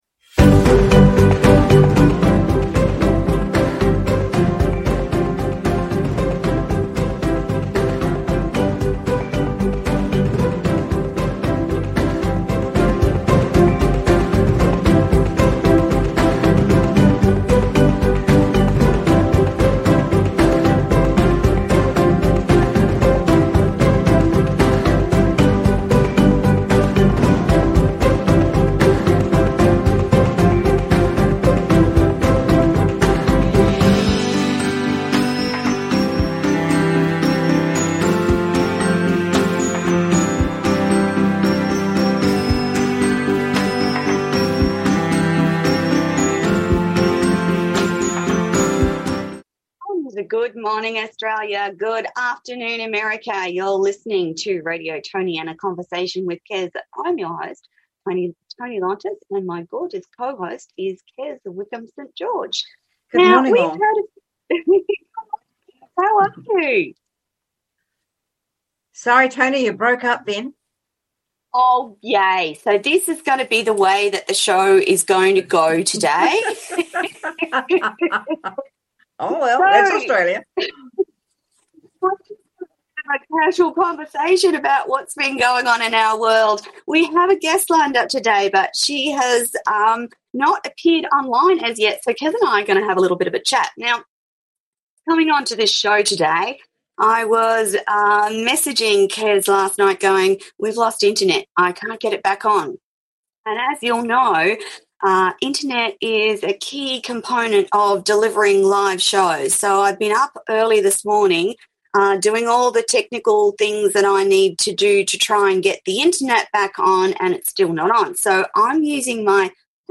Talk Show Episode
This show series is focused on authors and co hosted with my amazing new co host